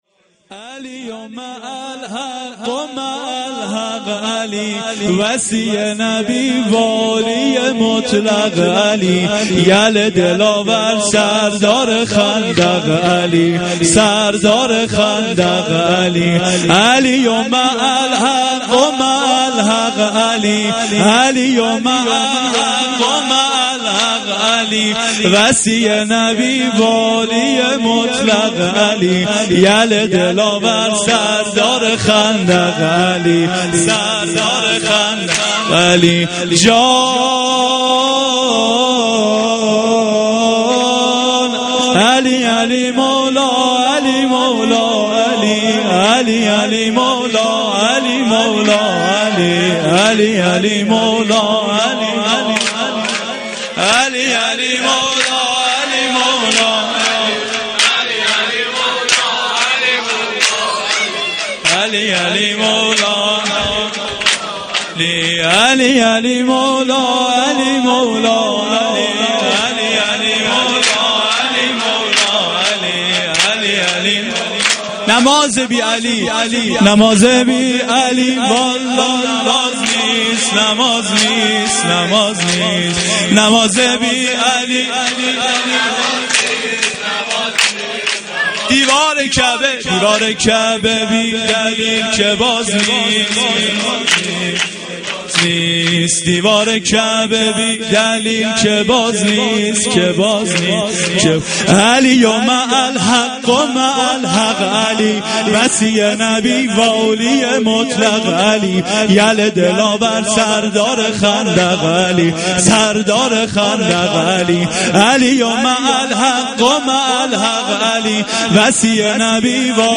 veladate-amiralmomenin-a-93-sorood-part3.mp3